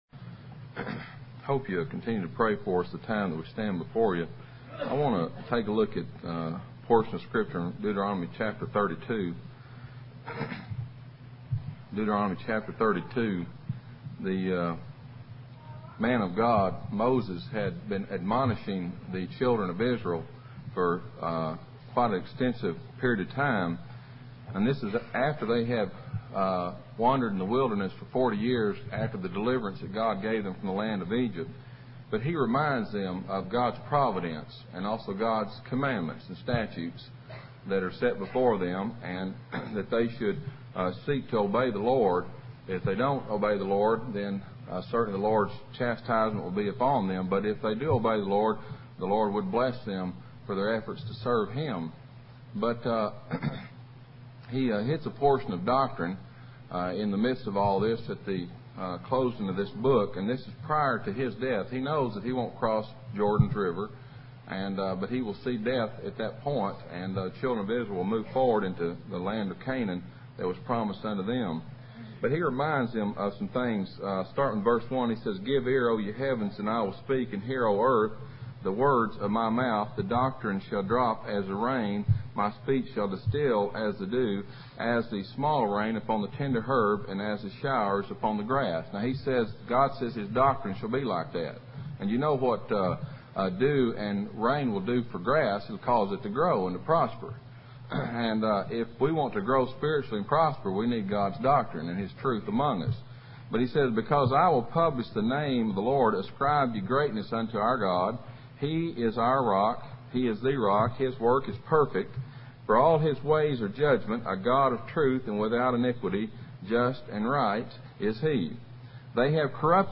Passage: Deuteronomy 23:0 Service Type: Middleton Creek PBC (MS) %todo_render% « Heb 1 vs 1-4 Faith »